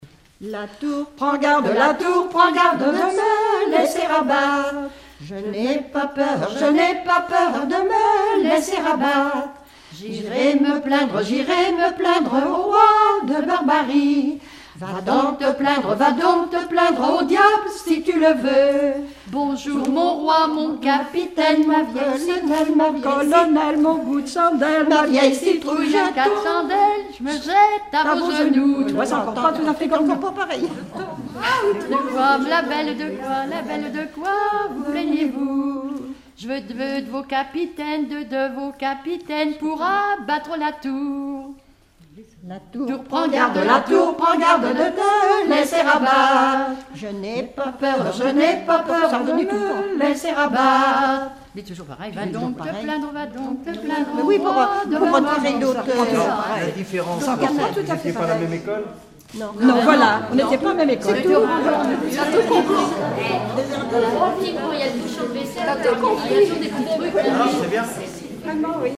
rondes enfantines (autres)
Regroupement de chanteurs du canton
Pièce musicale inédite